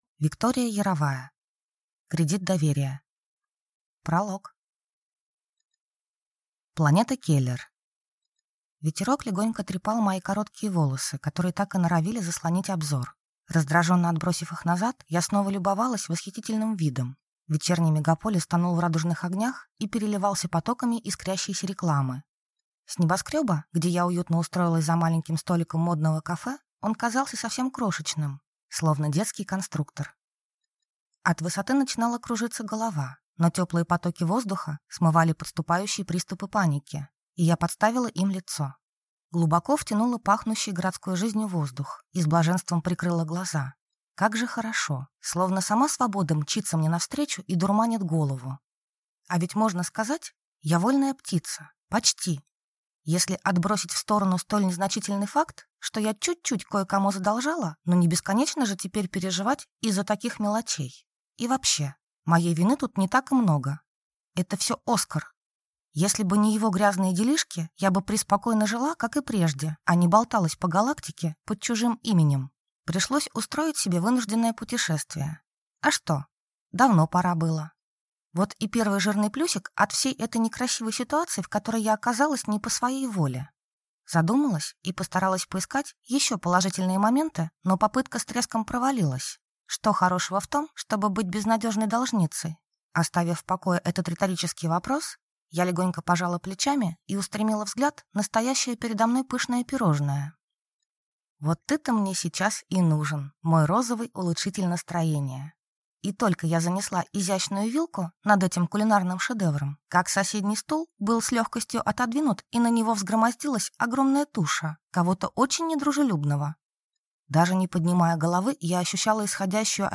Аудиокнига Кредит доверия | Библиотека аудиокниг